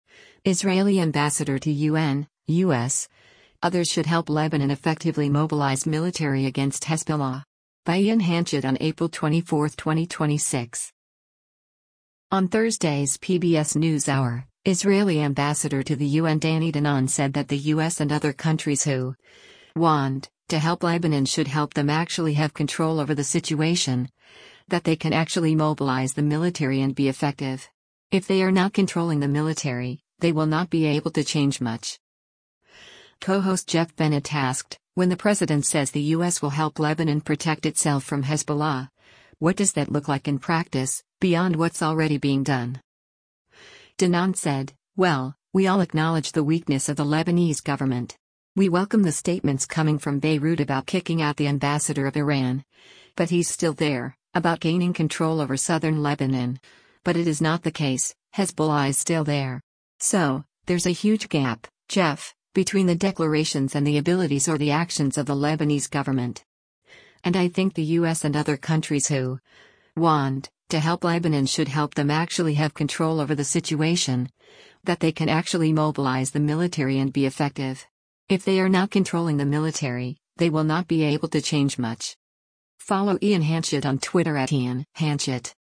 Co-host Geoff Bennett asked, “When the president says the U.S. will help Lebanon protect itself from Hezbollah, what does that look like in practice, beyond what’s already being done?”